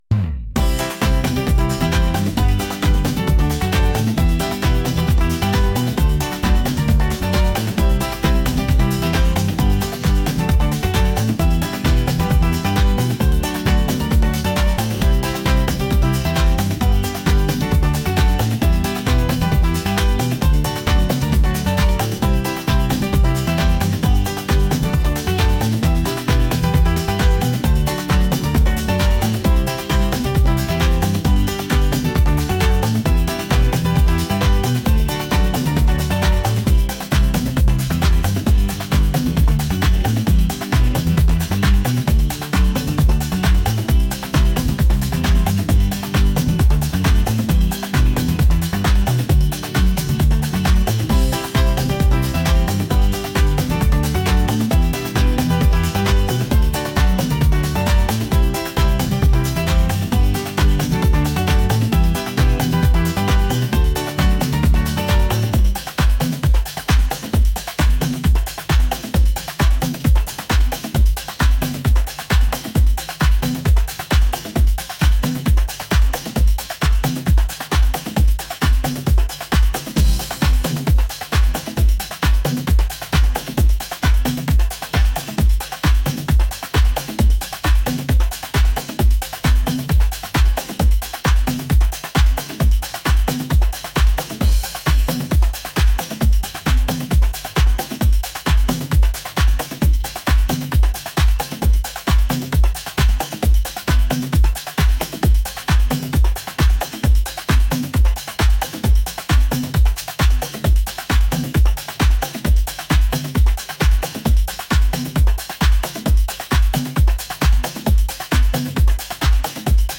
latin | upbeat